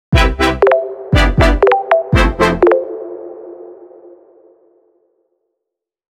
ELECTRONIC
MODERN ELECTRO JINGLE
Modern / Electronic / Uplifting / Positive